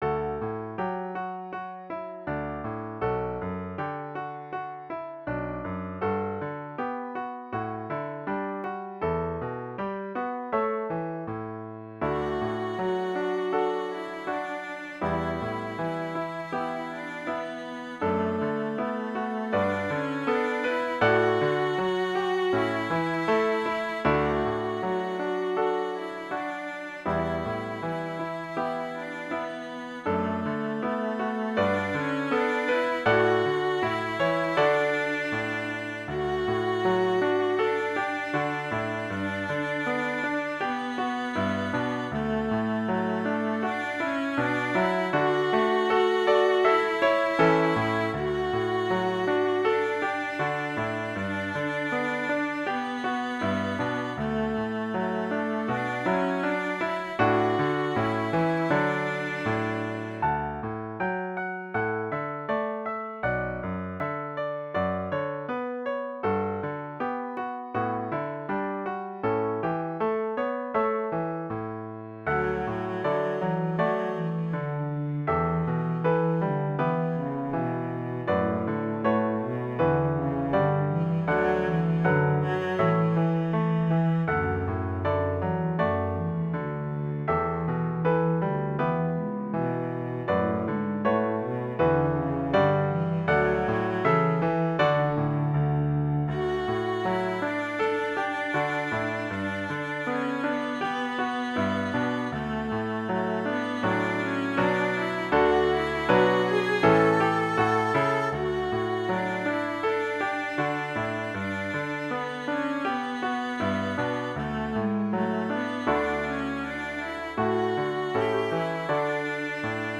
gentle, meditative mood
Intermediate Instrumental Solo with Piano Accompaniment.
Christian, Gospel, Sacred.